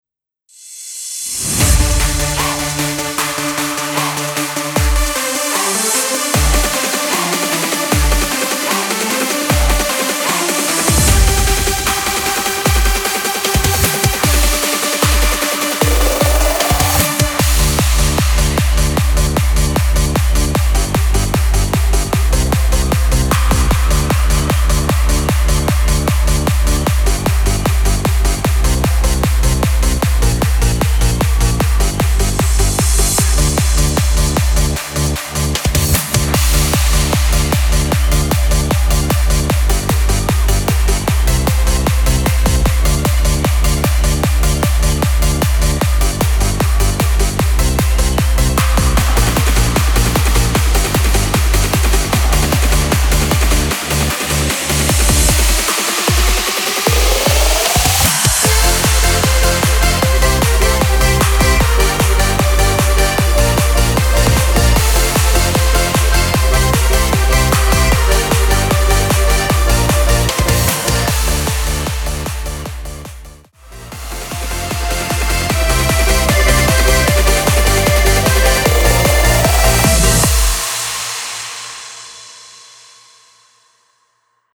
Karaoke Version: ja
Tonart: F Dur Karaoke Version ohne Chor